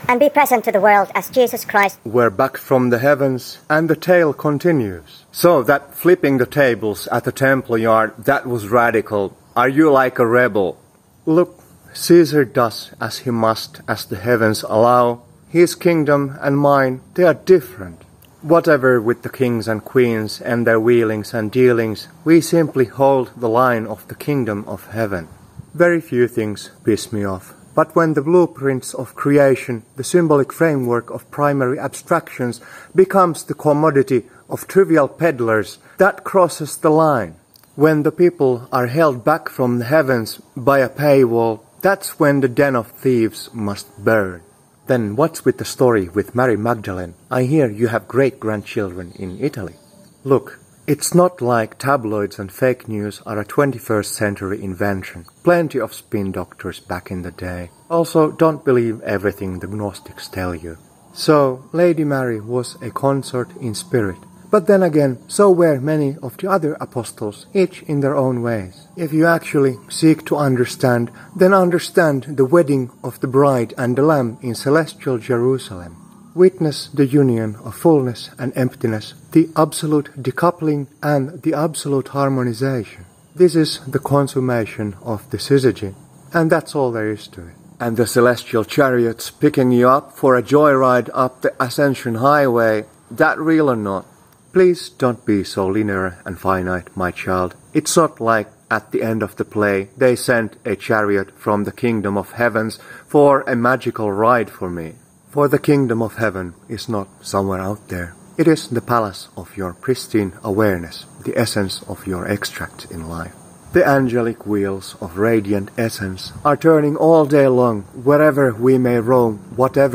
So much fake news and propaganda about Jesus. We're back from the heavens and the interview continues. Flipping the tables at the temple.